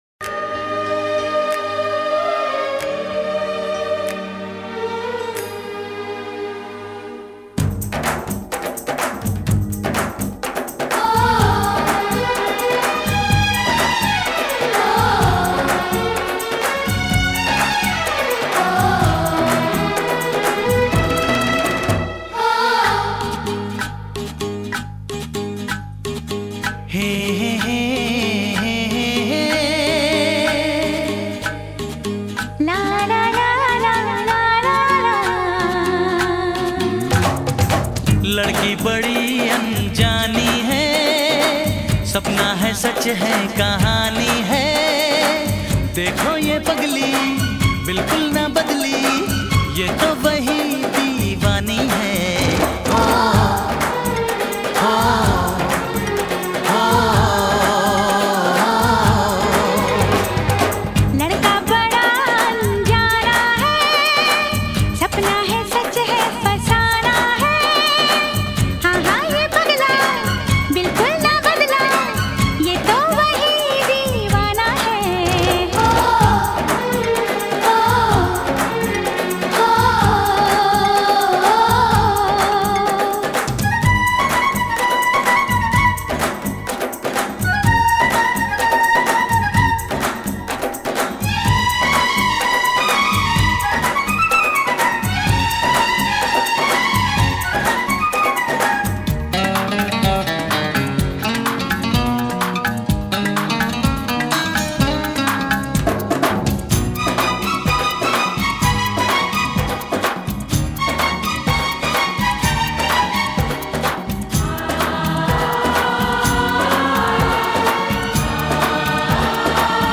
Carpeta: musica hindu mp3